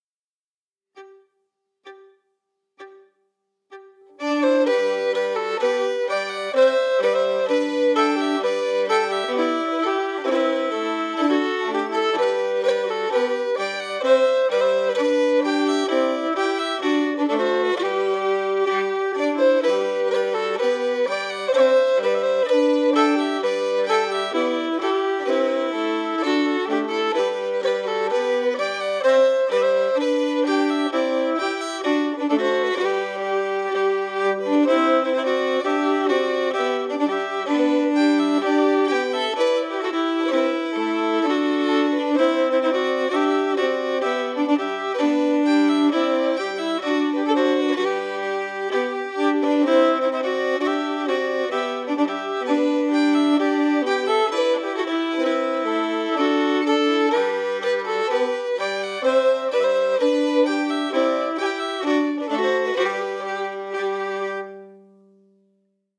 Key: G
Form: March
Harmony emphasis
Region: Cape Breton, Canada